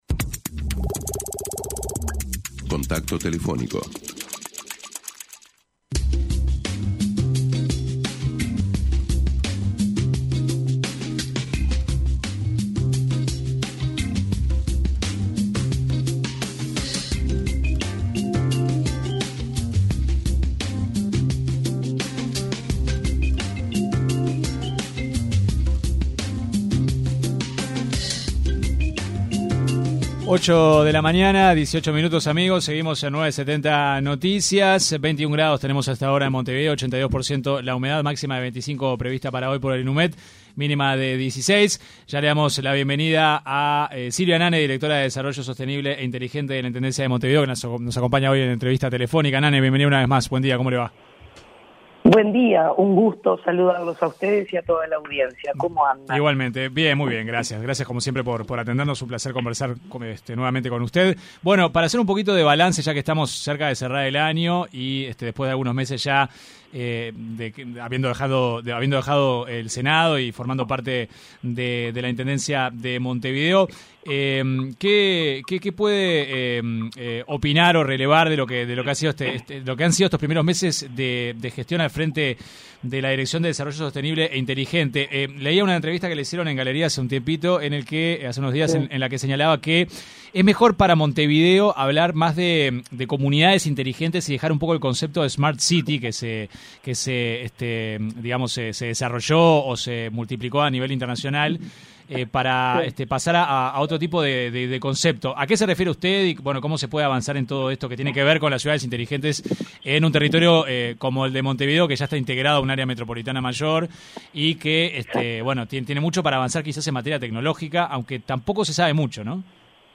La directora de Desarrollo Sostenible e Inteligente de la Intendencia de Montevideo, Silvia Nane, se refirió en una entrevista con 970 Noticias, al tema de los refugios peatonales.